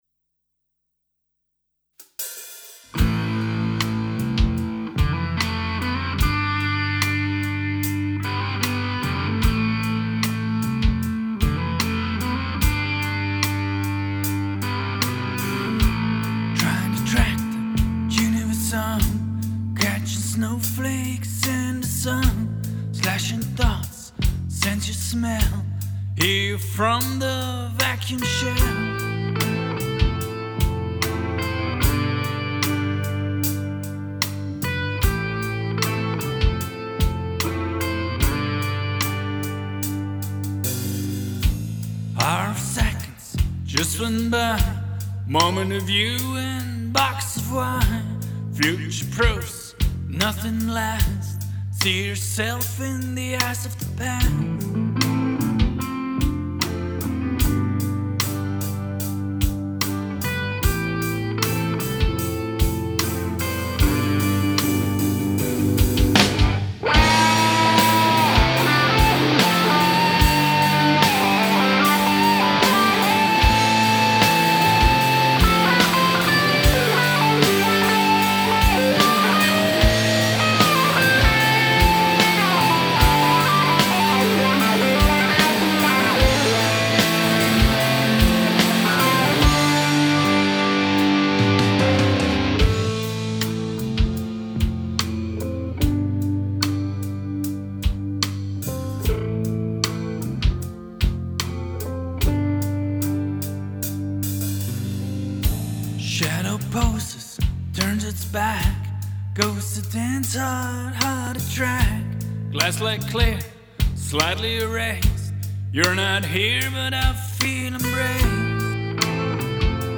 Alternative Rock Band needs a CD cover